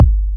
pcp_kick08.wav